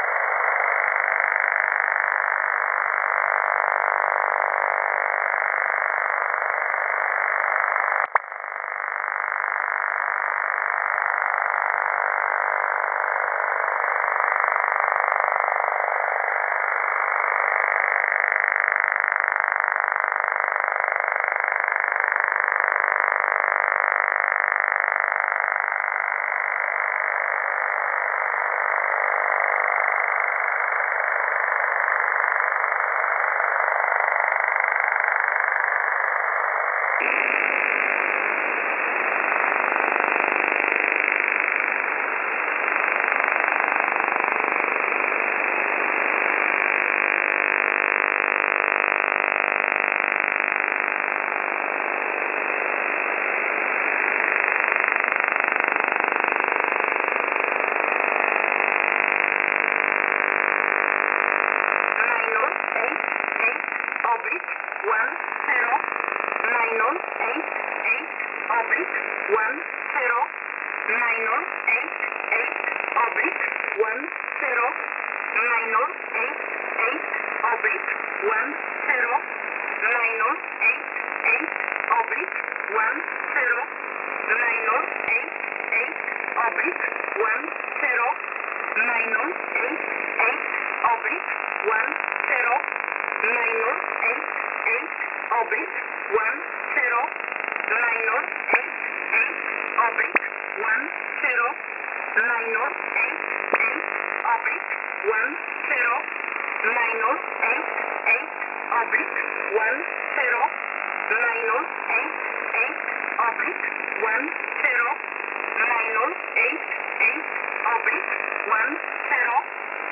Mode: USB Frequency: 10213